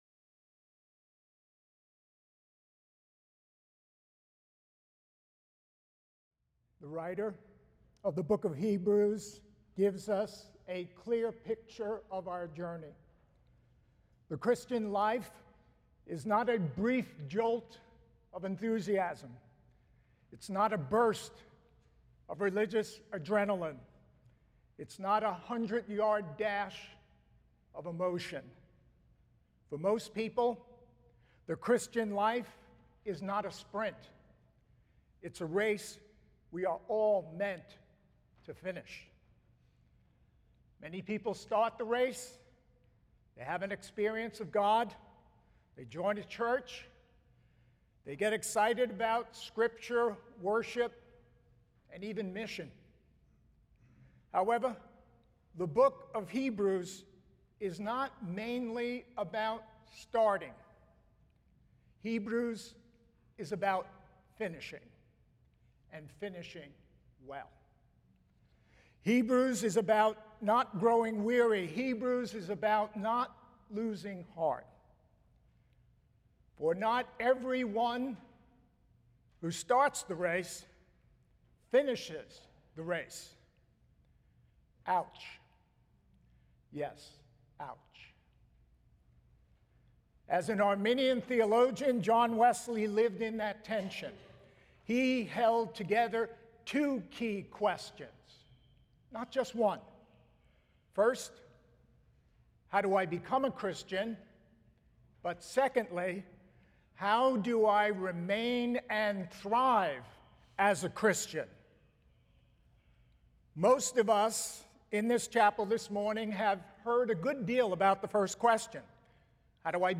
The following service took place on Thursday, March 12, 2026.